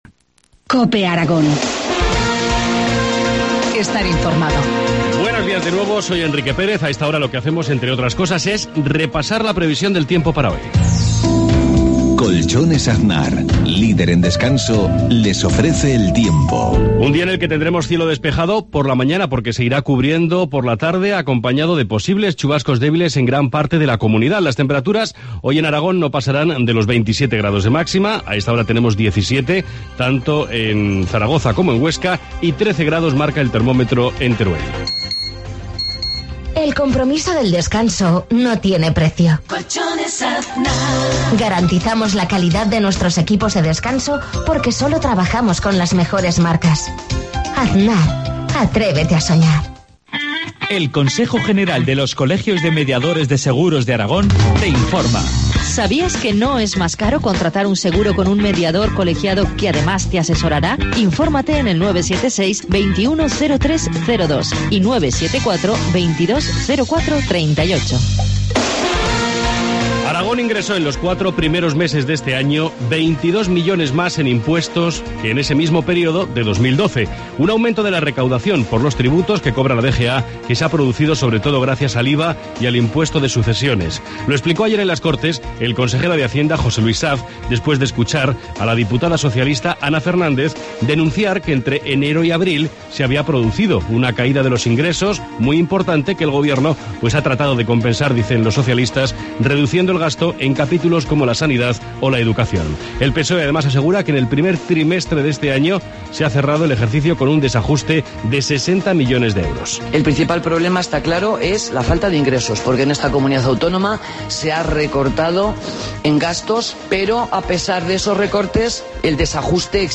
Informativo matinal, Jueves 6 junio 7,53 horas